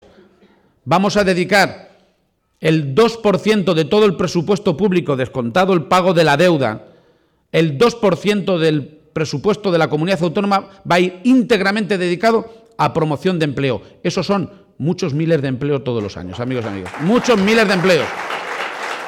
García-Page encaraba, pues, este último fin de semana de campaña con un acto público en Oropesa en el que sostenía que la prioridad en esta localidad, como en Talavera de la Reina, la ciudad más importante de esta comarca, la segunda en población y la primera por tasa de paro de Castilla-La Mancha, es el empleo, y decía que esta medida de dedicar el dos por ciento del presupuesto neto de la Junta a este problema «resume seguramente todas las medidas que hemos ido presentando para luchar contra el paro».